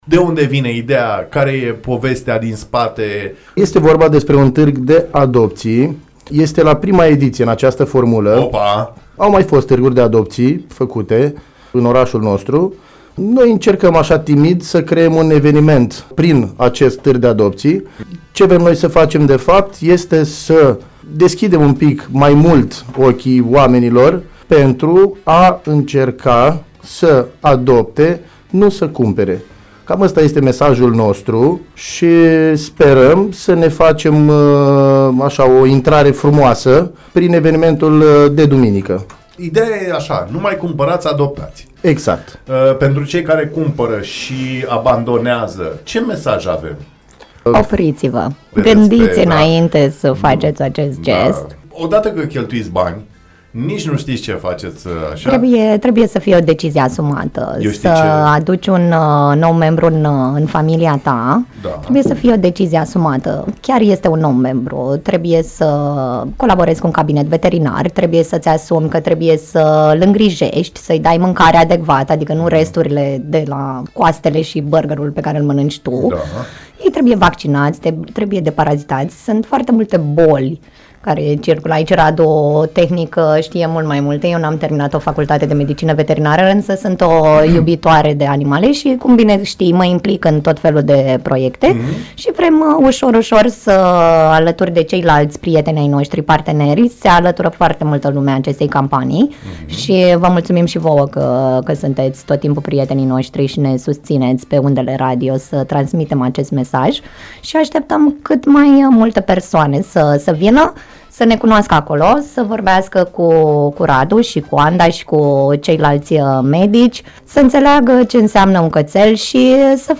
Invitați în studio